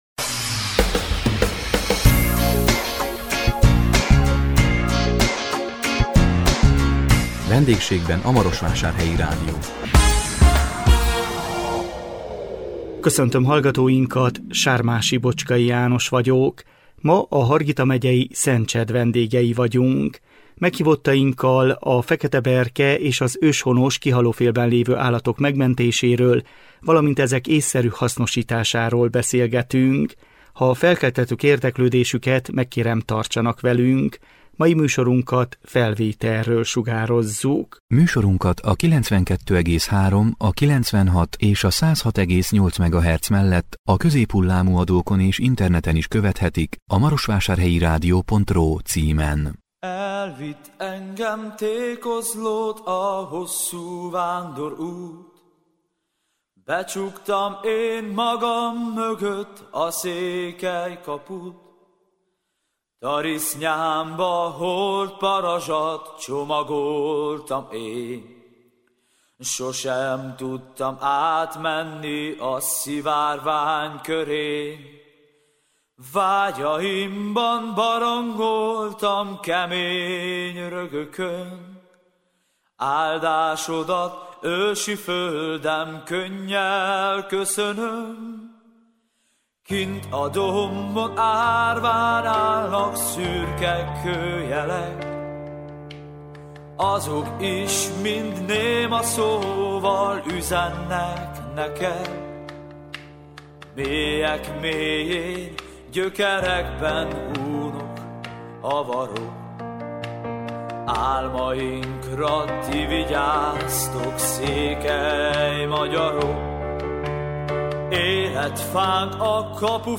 A 2021 augusztus 26-án jelentkező VENDÉGSÉGBEN A MAROSVÁSÁRHELYI RÁDIÓ című műsorunkban a Hargita megyei Szencsed vendégei voltunk. Meghívottainkkal a fekete berke és az őshonos, kihalófélben lévő állatok megmentéséről valamint ezek ésszerű hasznosításáról beszélgettünk.